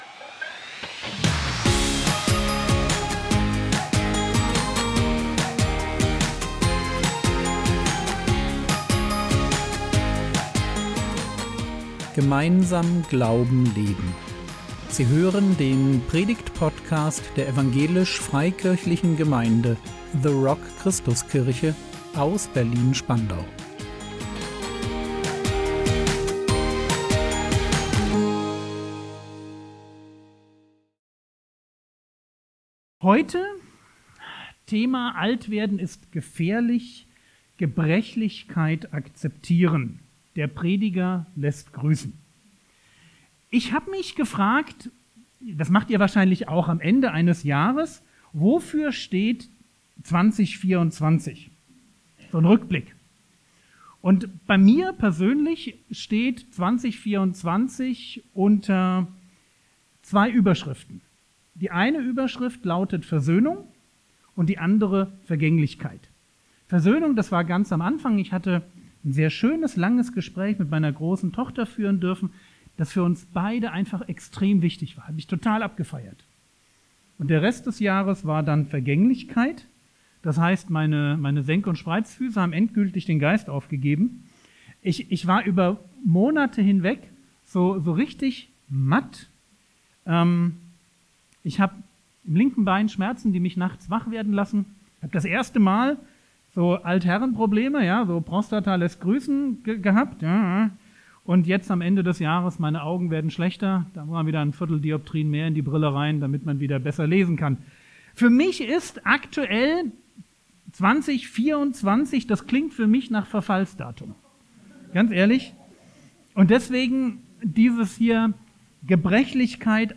Altwerden ist gefährlich - Teil 3 | 15.12.2024 ~ Predigt Podcast der EFG The Rock Christuskirche Berlin Podcast